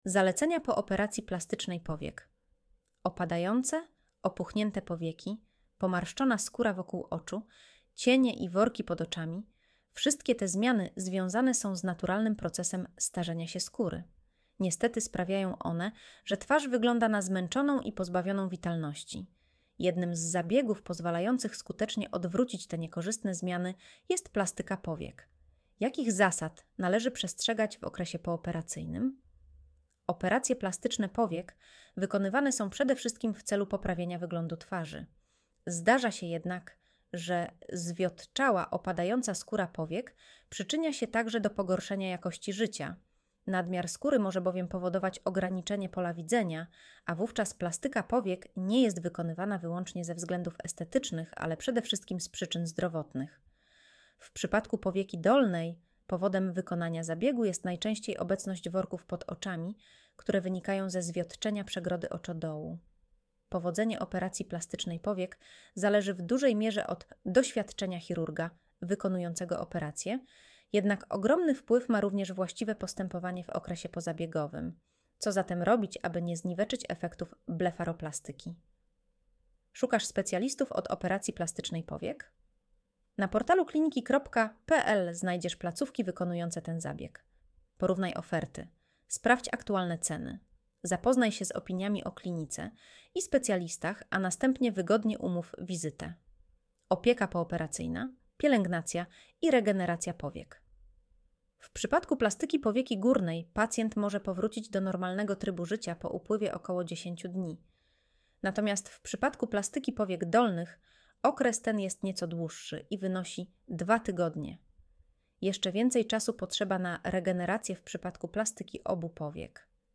Streść artykuł Słuchaj artykułu Audio wygenerowane przez AI, może zawierać błędy 00:00